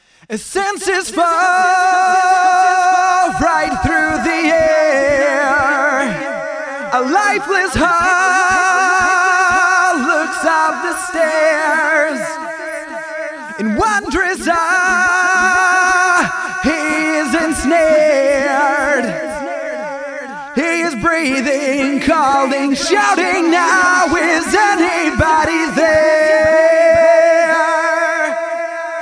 4声の美しいハーモニーとリズミカルなディレイを生み出す、直感的なピッチシフター
Quadravox | Vocals | Preset: Funky Rhythmic
Vocals-Funky-Rythmic.mp3